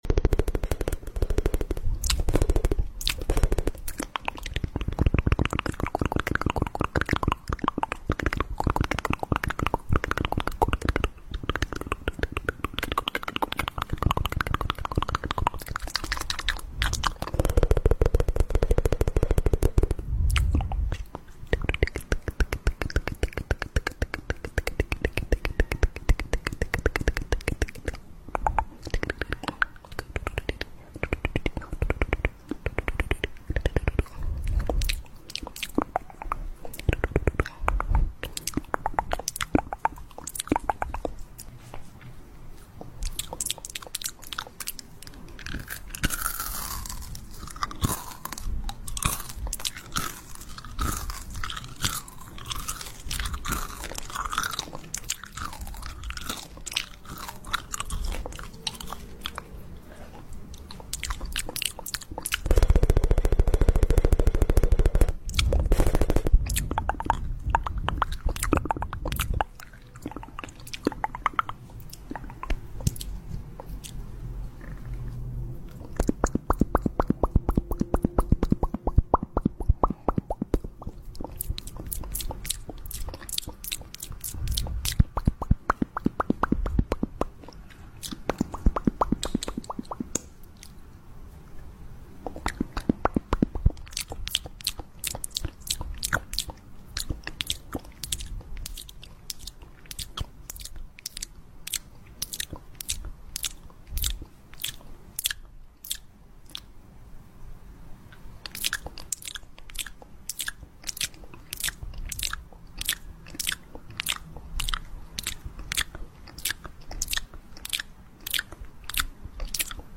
fluttering asmr 👄